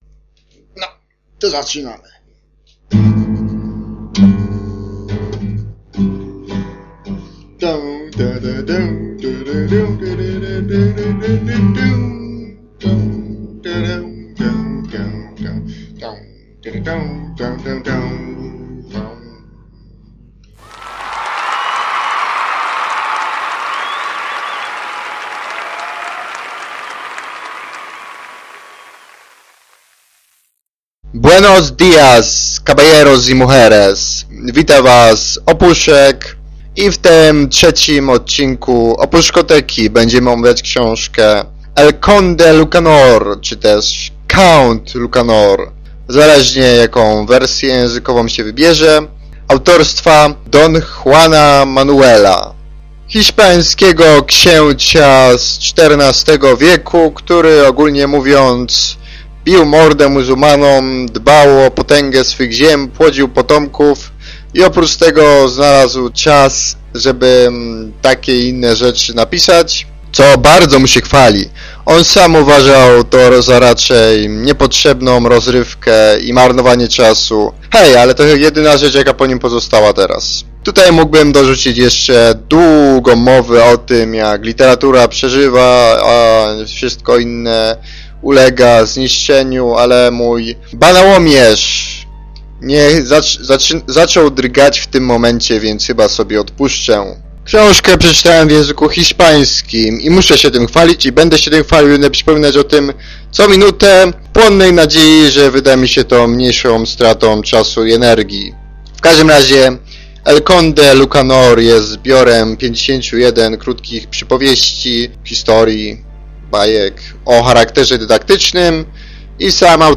Wstęp gitarowy miał być dłuższy, ale okoliczna ludność zdołała mnie rozbroić.